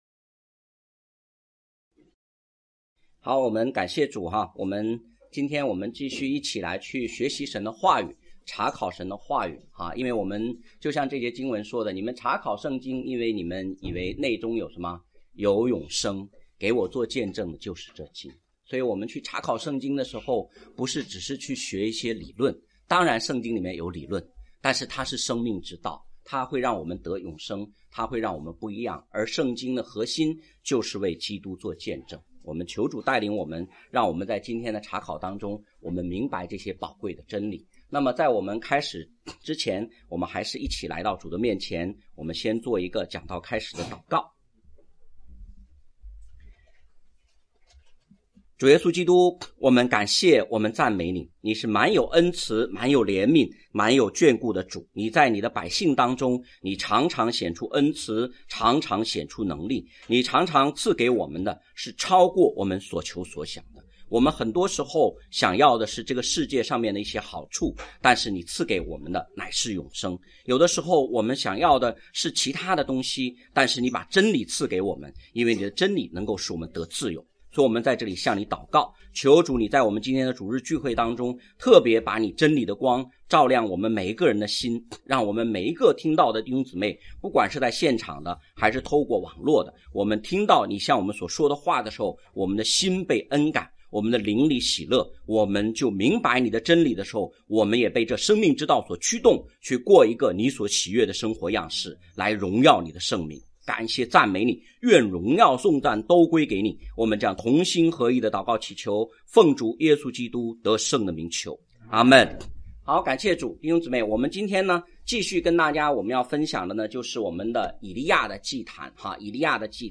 讲道录音 请点击音频媒体前面的小三角“►”就可以播放 了： https